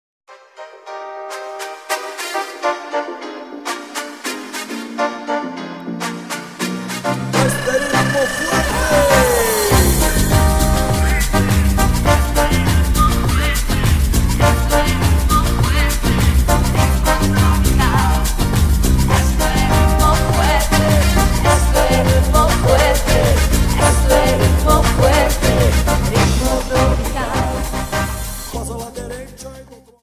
Samba 51 Song